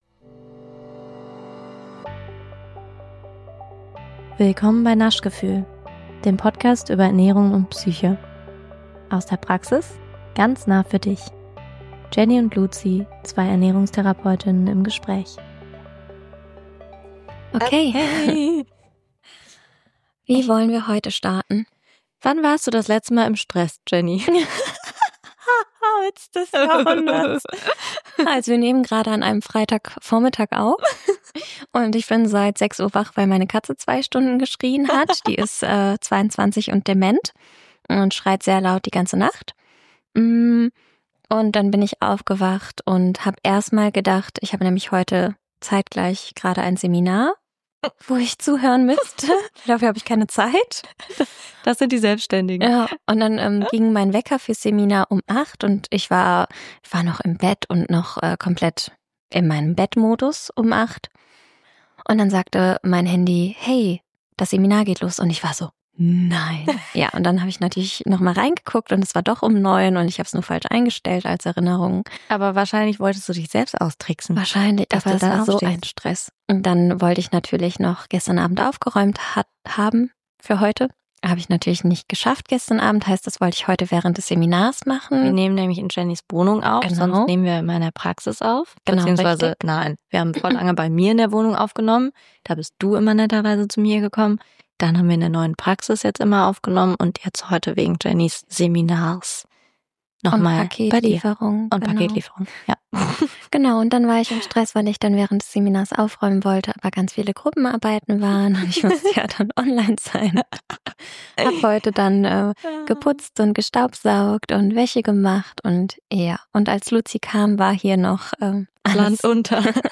Was sind Vor- sowie Nachteile? Hör gern in unser Gespräch rein!